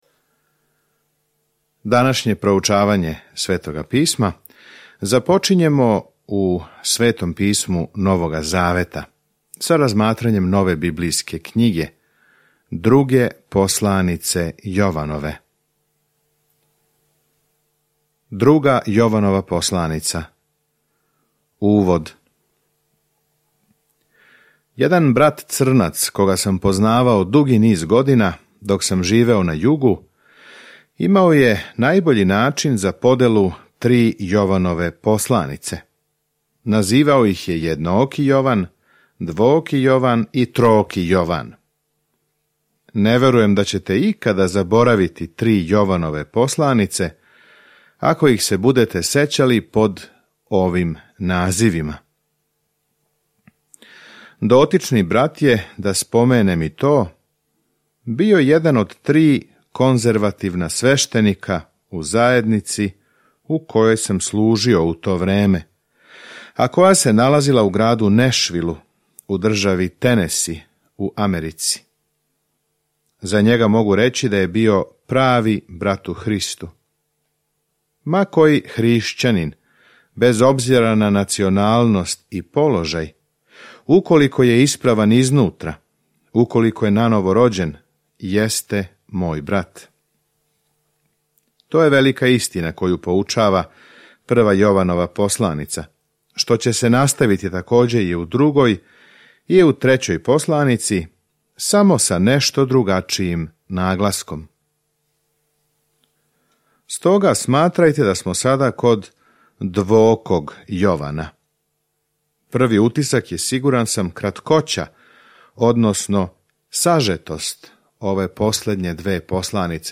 Jovanova 1:1 Započni ovaj plan Dan 2 O ovom planu Ово друго Јованово писмо помаже великодушној жени и локалној цркви да знају како да изразе љубав унутар граница истине. Свакодневно путујте кроз 2. Јованову док слушате аудио студију и читате одабране стихове из Божје речи.